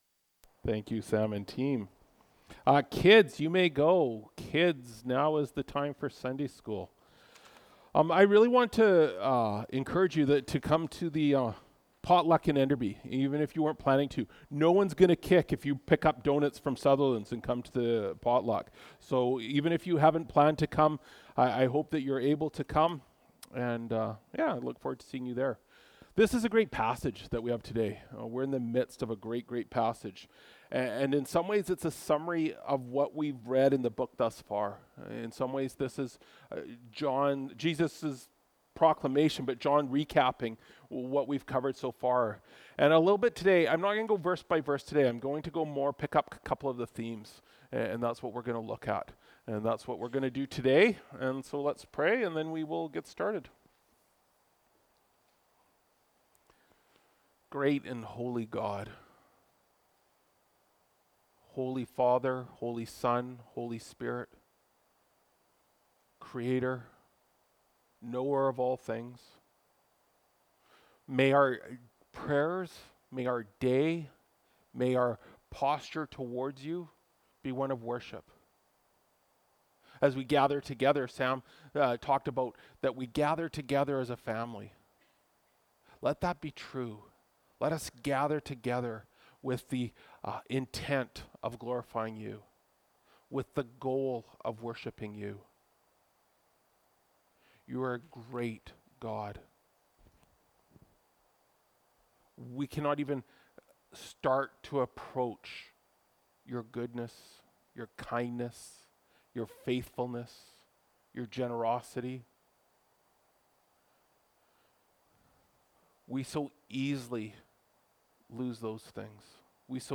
Apr 28, 2024 God’s Glory Part 2 (John 17:9-19) MP3 SUBSCRIBE on iTunes(Podcast) Notes Discussion Sermons in this Series This sermon was recorded at Grace Church Salmon Arm and preached in Grace Church Enderby.